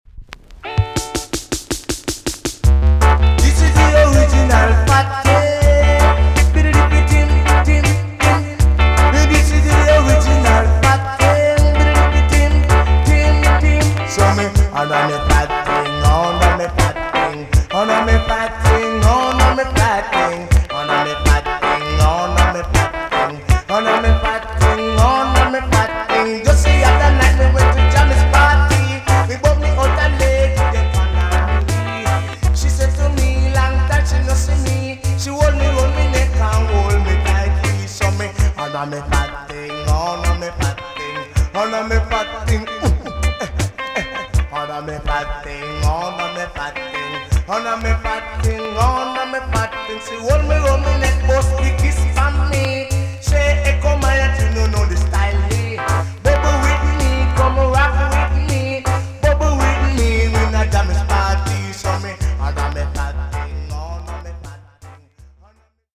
TOP >80'S 90'S DANCEHALL
EX- 音はキレイです。
WICKED OUT OF KEY STYLE!!